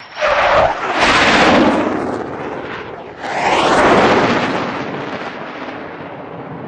F-104 Starfighter
F-104 Away Time Compressed